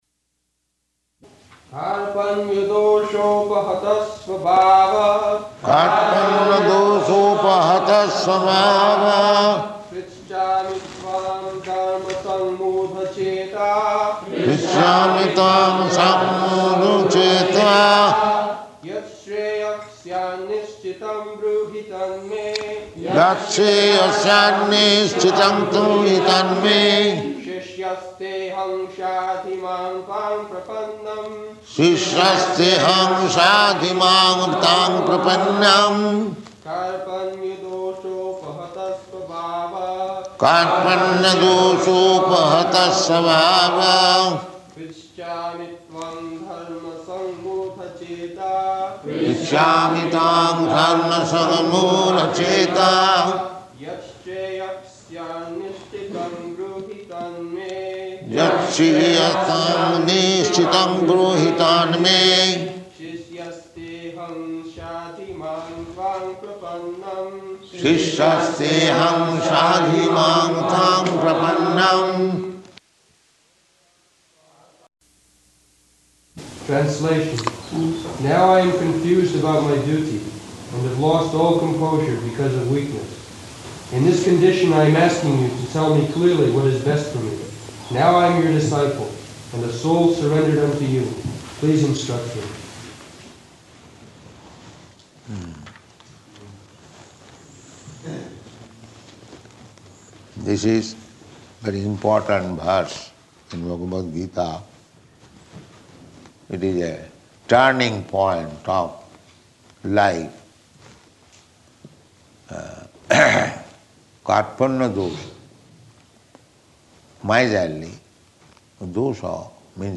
Location: London